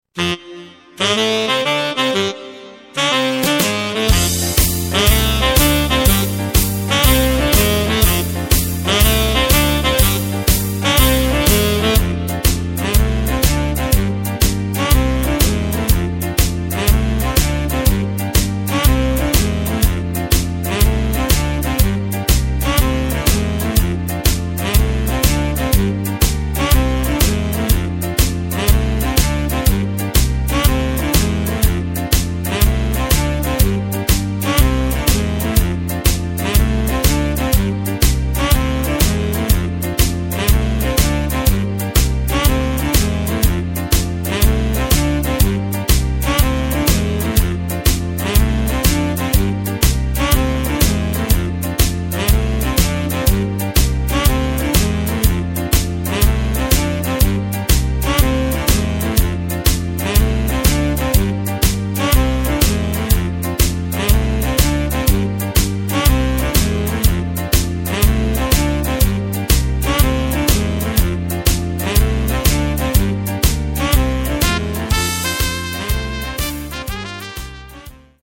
Takt:          4/4
Tempo:         122.00
Tonart:            C
Jive aus dem Jahr 2012!
Playback mp3 Demo